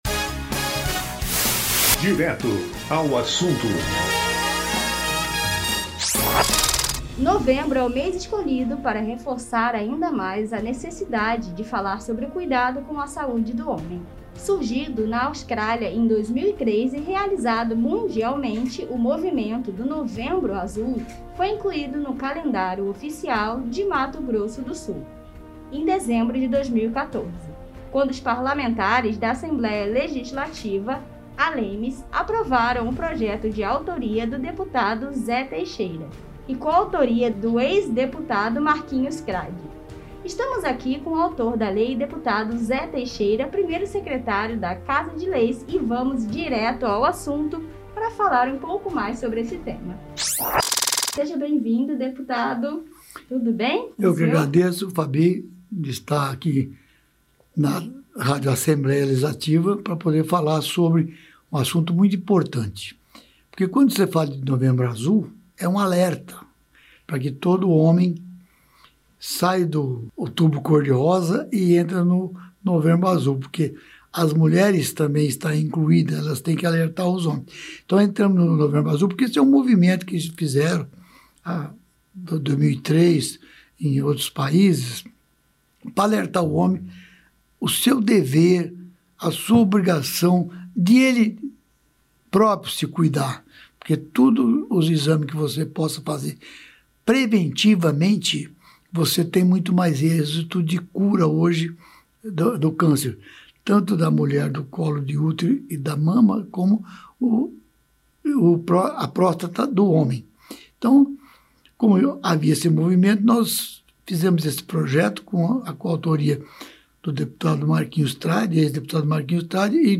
Instituído no calendário estadual de Mato Grosso do Sul desde 2014, o novembro azul, PL de autoria do Deputado Zé Teixeira, trás diversas campanhas de prevenção ao câncer de próstata. E para esse bate papo, o programa Direto ao Assunto, trouxe o autor deste projeto.